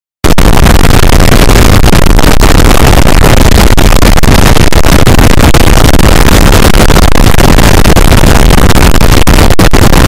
Звуки triggered
Звук триггеред когда красные глаза выпучены